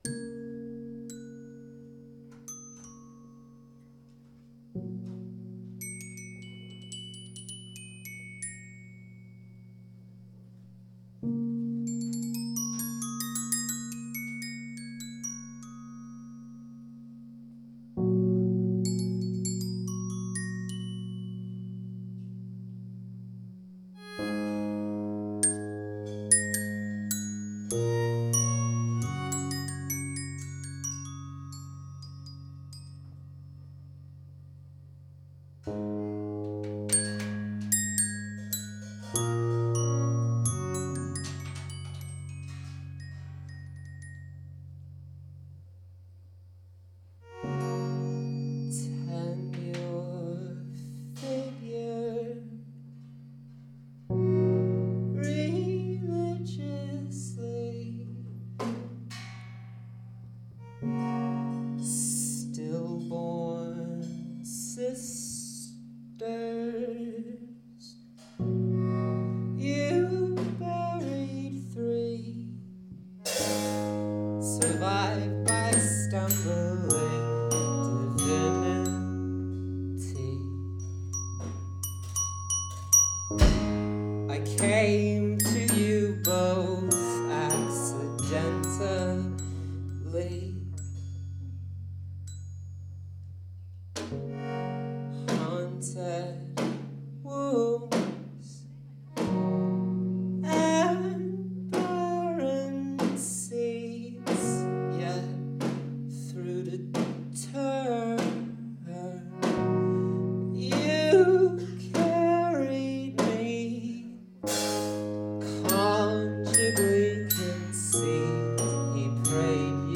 2006-11-03 Paradox Theatre – Seattle, WA